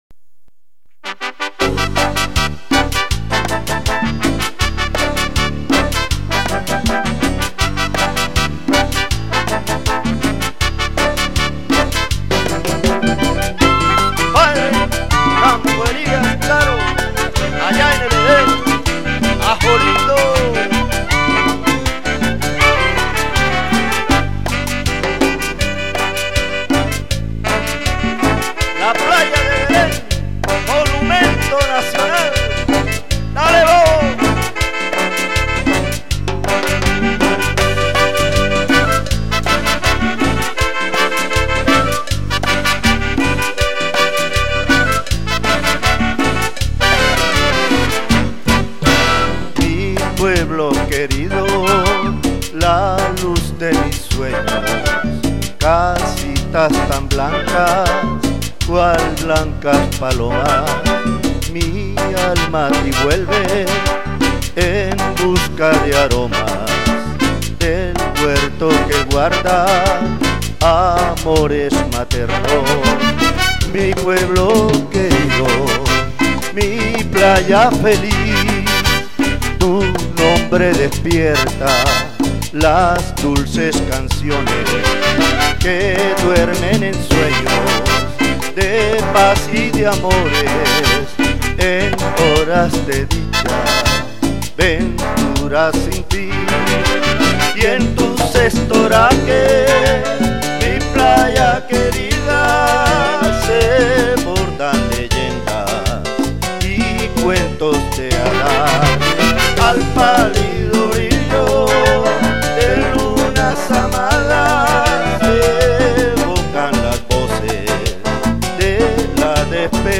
porro
con una orquesta en Cali por los años 80